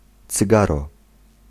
Ääntäminen
US : IPA : [sɪ.ˈgɑɹ]